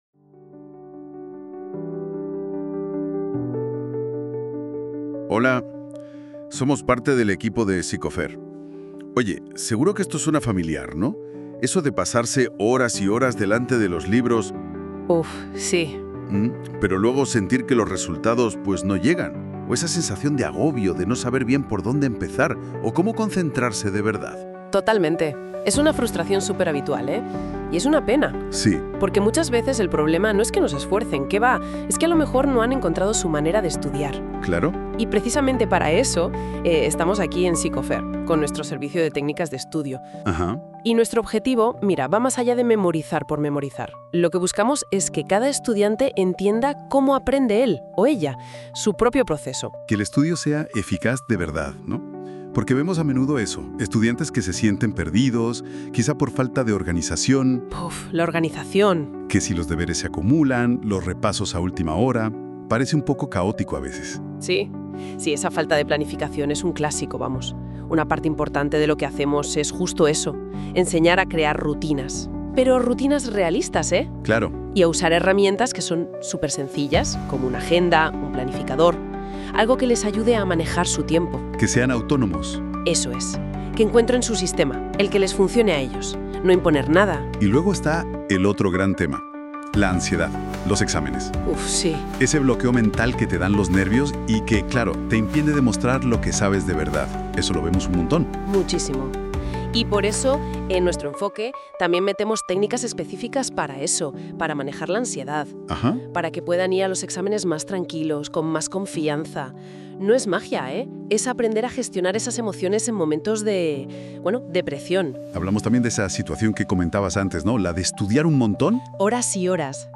Si no te apetece leer, en este episodio de nuestro podcast te resumimos todo lo que necesitas saber sobre nuestro enfoque de terapia infantil en una conversación clara y cercana.